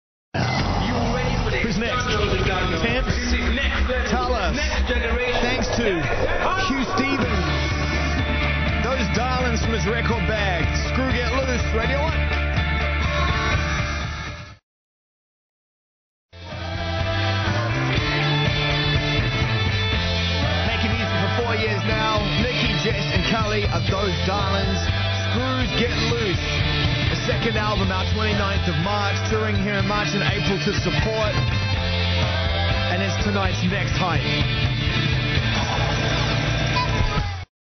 Music cue